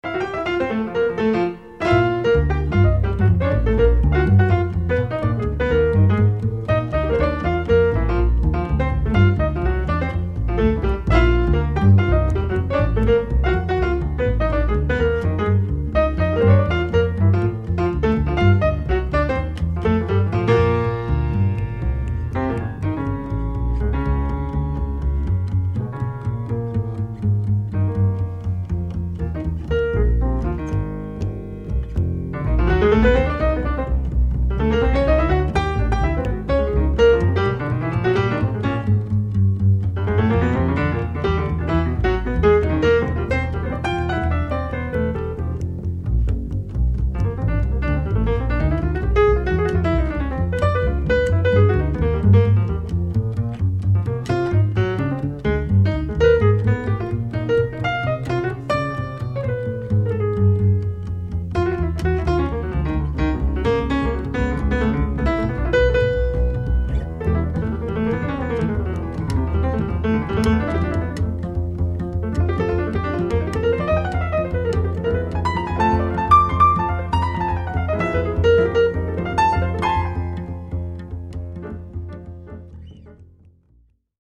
Recorded live at Keystone Korner, San Francisco, July, 1981